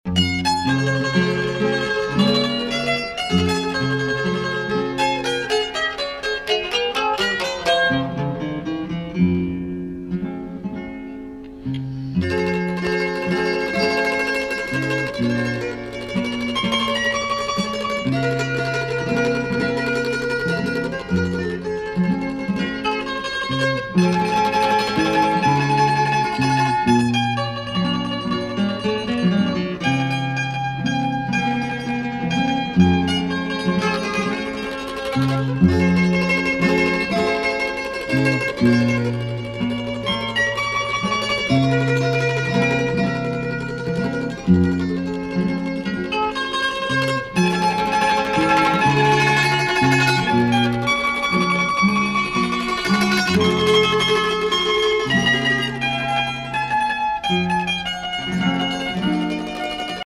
• Теги: минусовка
Минусовка.